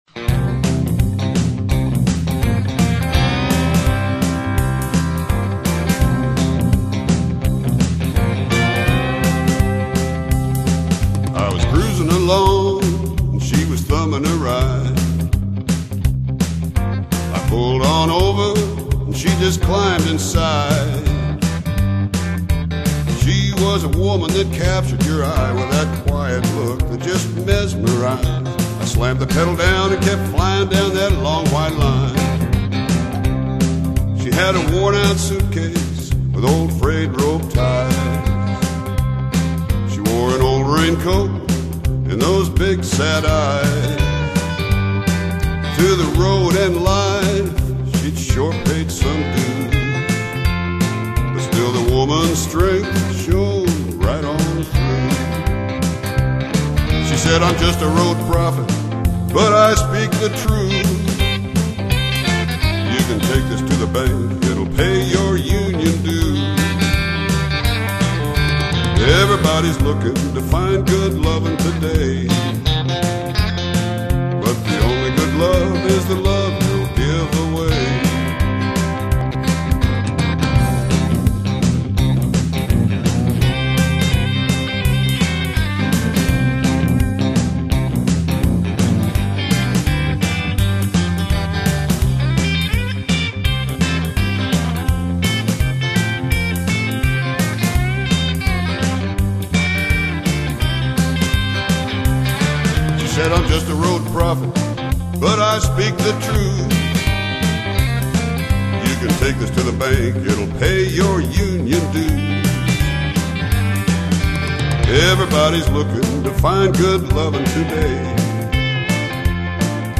classic country feel
harmonica
rhythm guitar
drums
hot country and rock sound